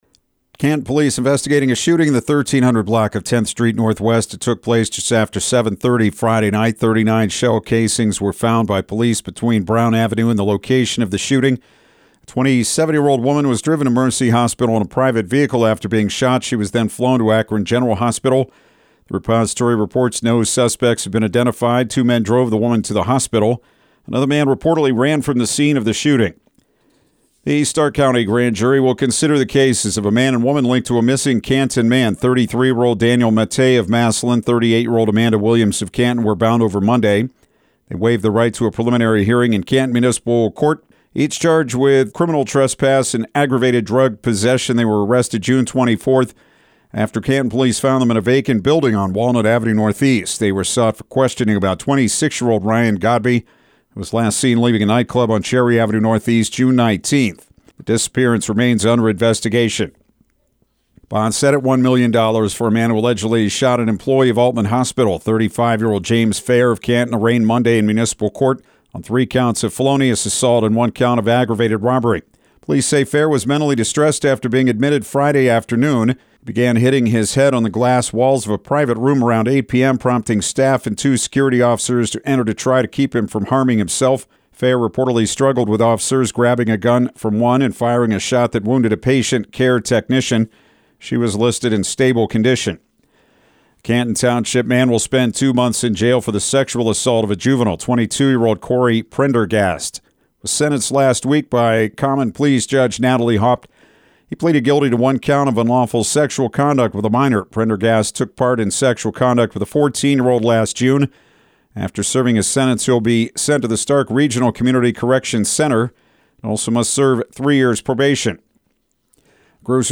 Afternoon News